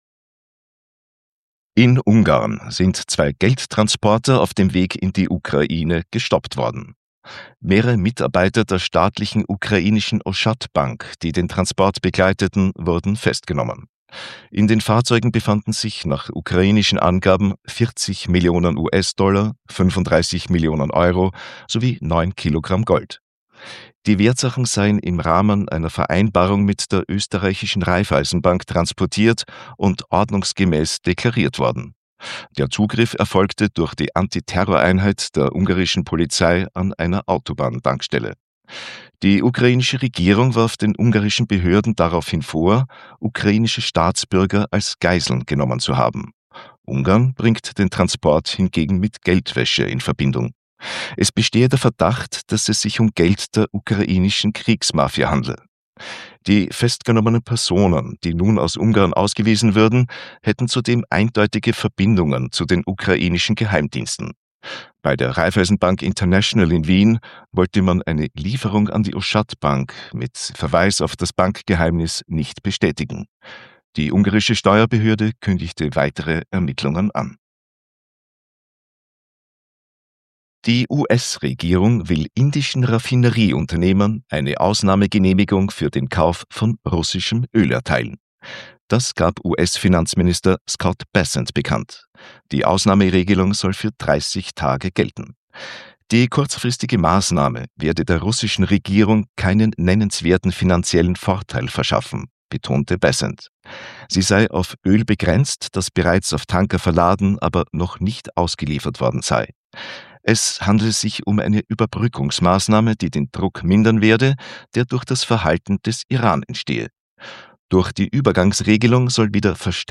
Kontrafunk Wochenrückblick 7.3.2026 – Nachrichten vom 7.3.2026